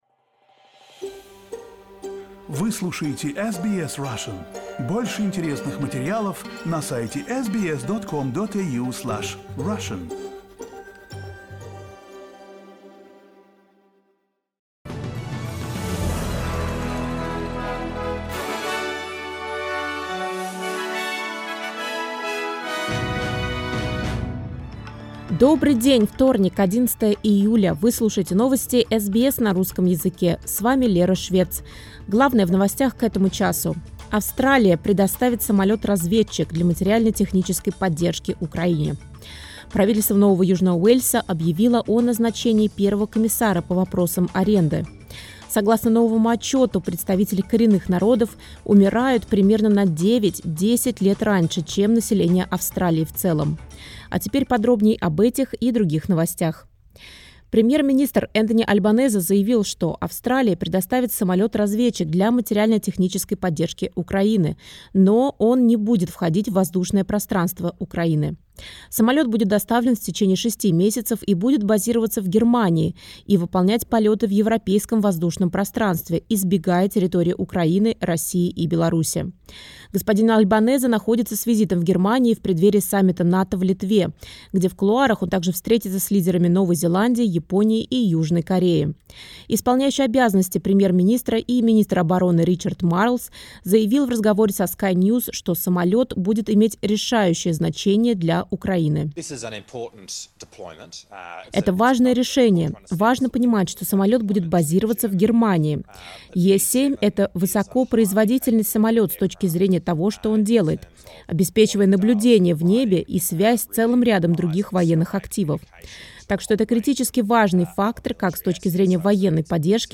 SBS news in Russian — 11.07.2023